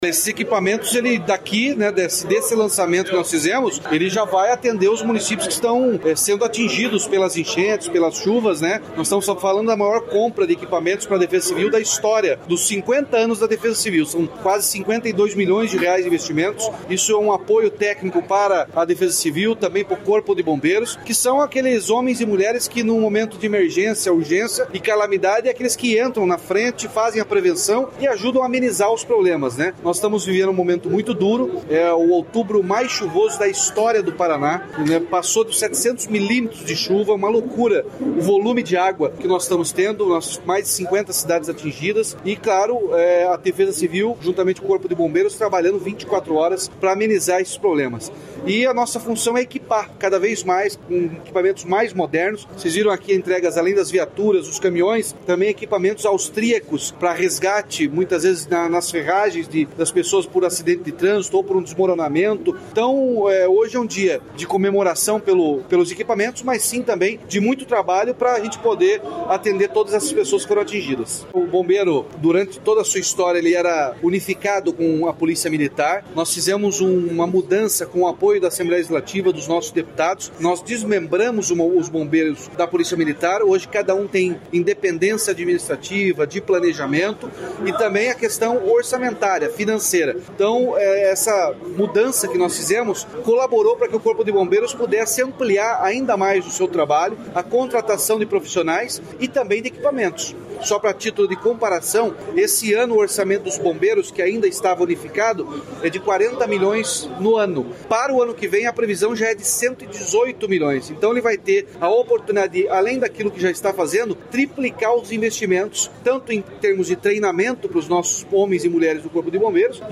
Sonora do governador Ratinho Junior sobre a entrega de R$ 52 milhões em veículos e equipamentos para a Defesa Civil